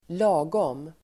Uttal: [²l'a:gåm]